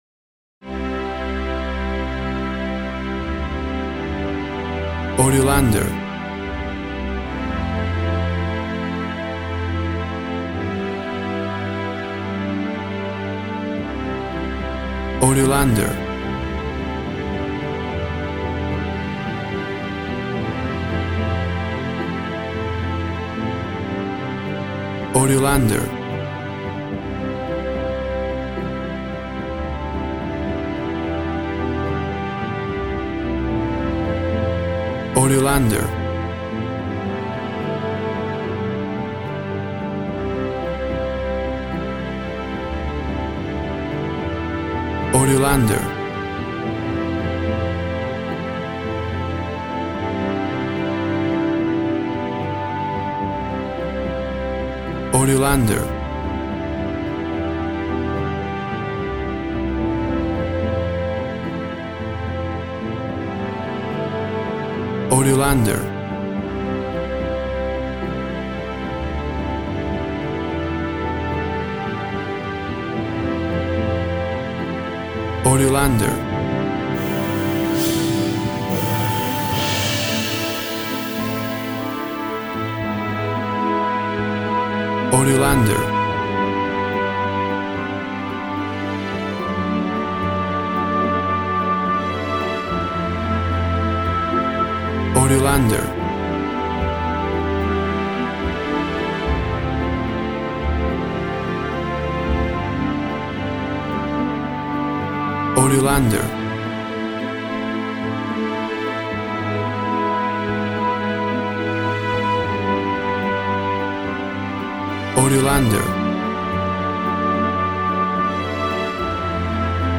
Melodic inspirational. Emotions.
Tempo (BPM) 72